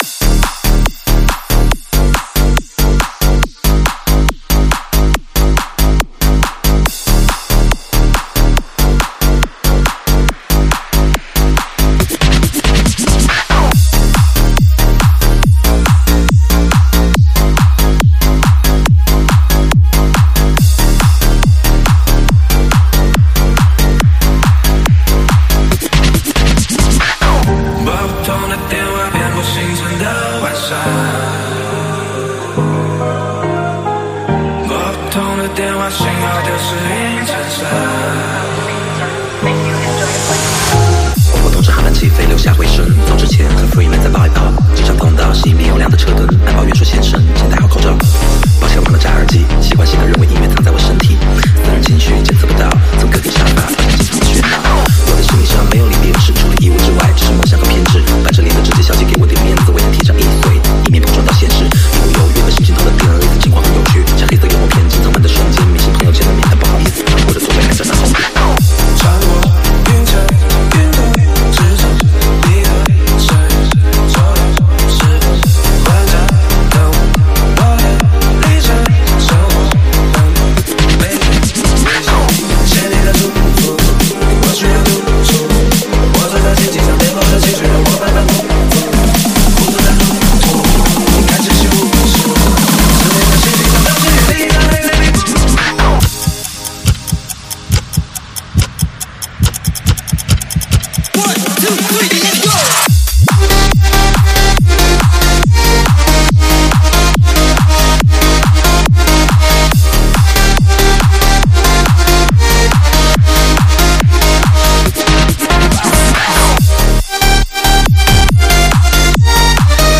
试听文件为低音质，下载后为无水印高音质文件 M币 10 超级会员 M币 5 购买下载 您当前未登录！